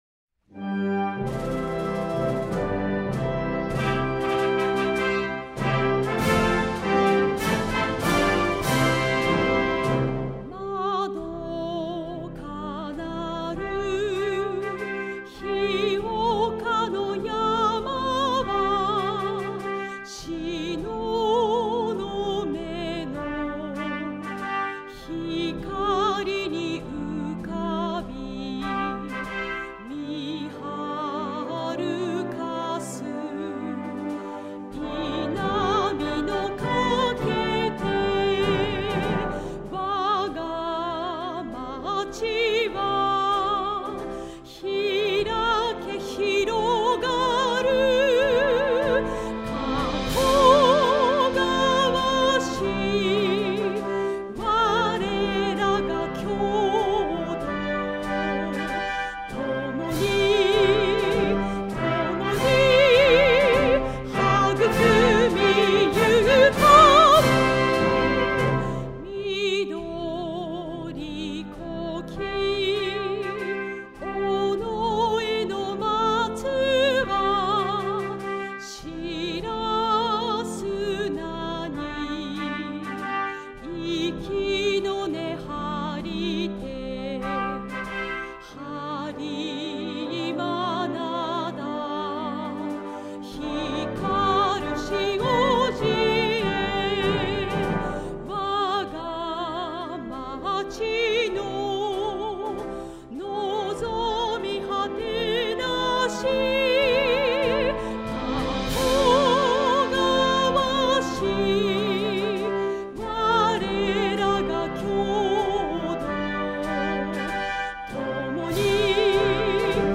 演奏：陸上自衛隊中部方面音楽隊